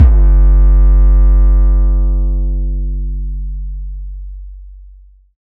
808 Kick 27_DN.wav